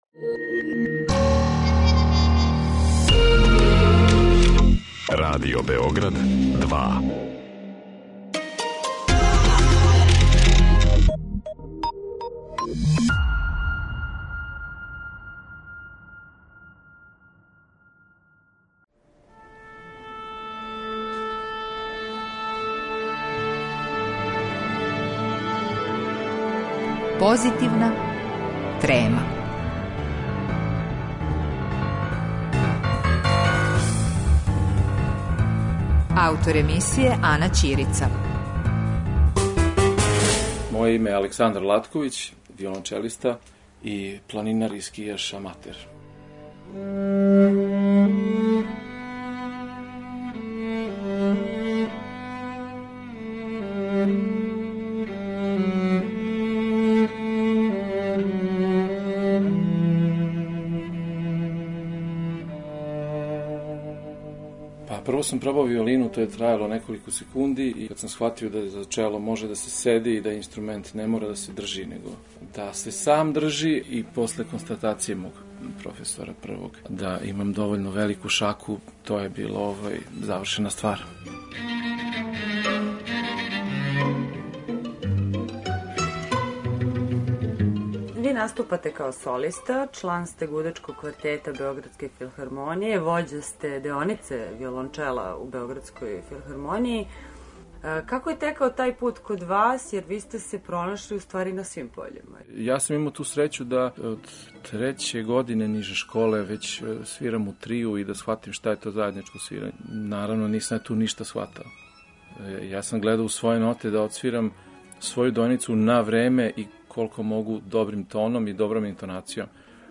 Разговор са виолончелистом